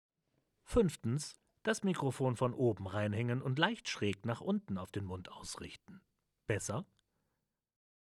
Sprechprobe
5. Das Mikrofon von oben reinhängen und leicht schräg nach unten auf den Mund ausrichten. Besser?
Puh, ich höre mit DT 770 pro ab und kann nur sehr marginale Unterschiede hören.